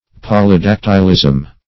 Search Result for " polydactylism" : The Collaborative International Dictionary of English v.0.48: Polydactylism \Pol`y*dac"tyl*ism\, n. [Poly- + Gr.
polydactylism.mp3